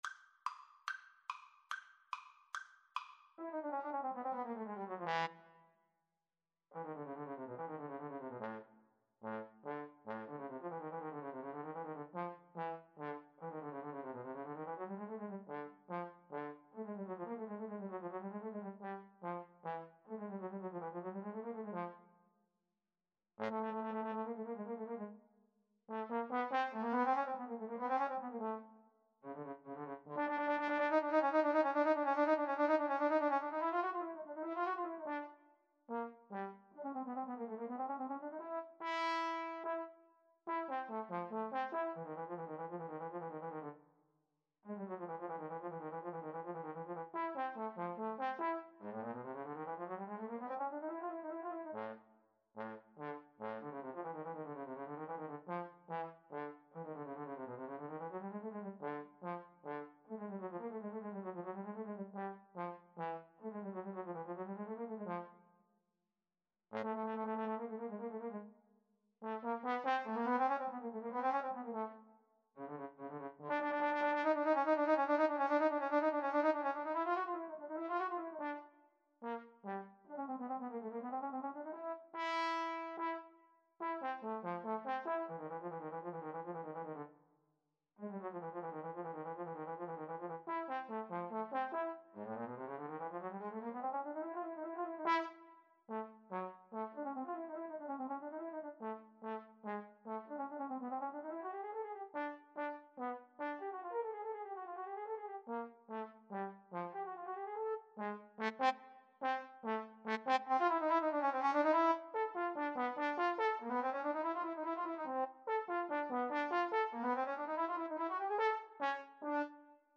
Vivace = 144 (View more music marked Vivace)
A minor (Sounding Pitch) (View more A minor Music for Trombone Duet )
Classical (View more Classical Trombone Duet Music)